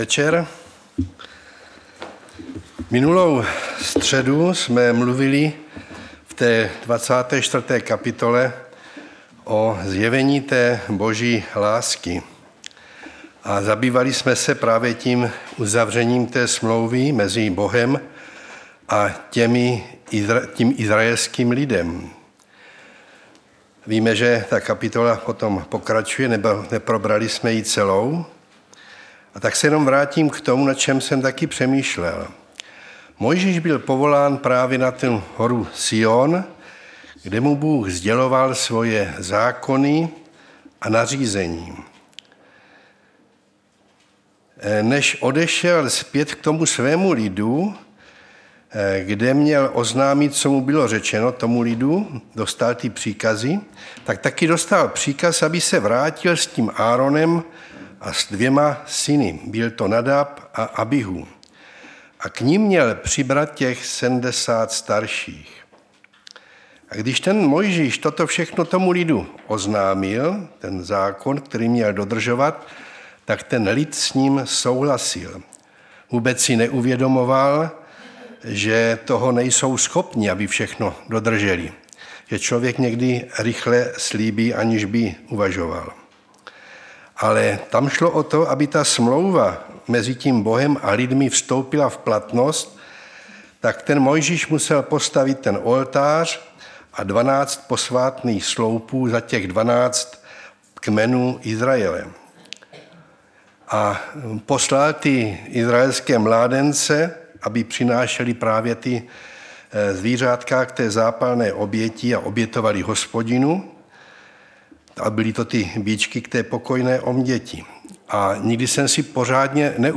Středeční vyučování